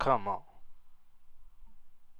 COMEON.WAV